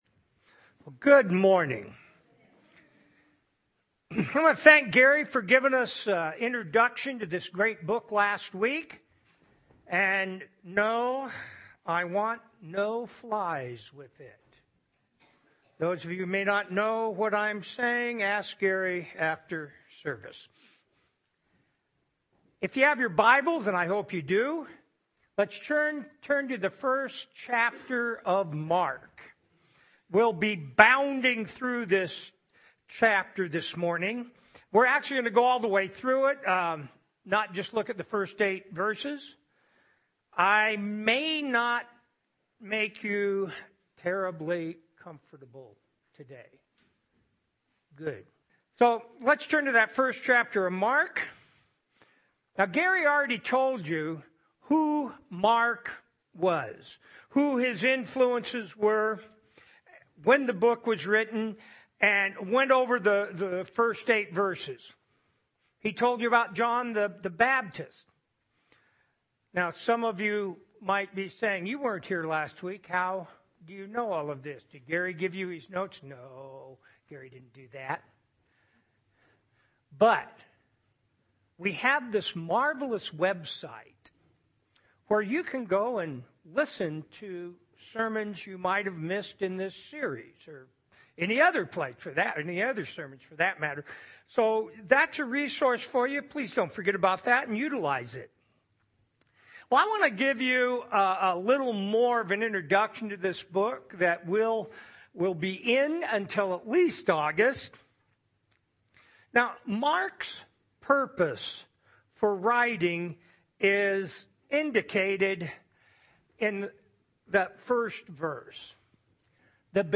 Sermon
Special Music